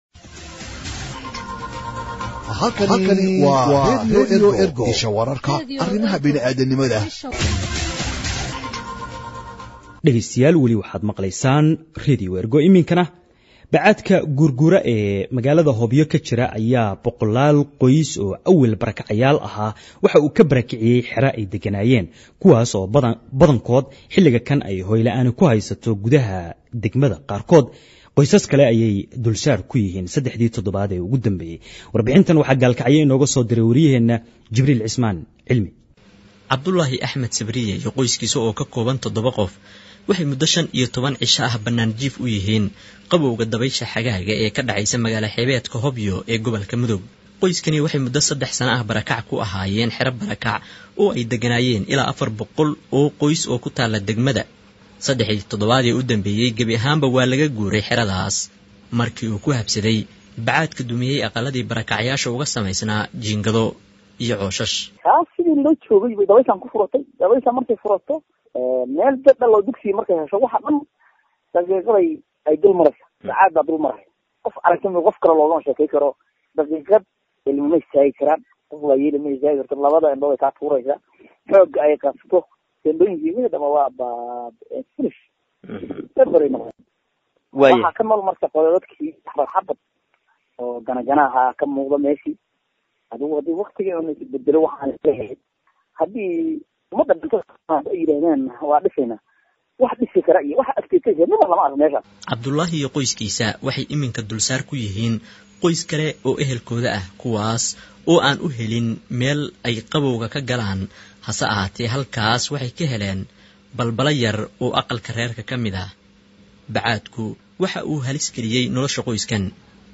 Radio Ergo provides Somali humanitarian news gathered from its correspondents across the country for radio broadcast and website publication.
Warbixinta-Bacaadka-Guur-guura-ee-Hobyo.mp3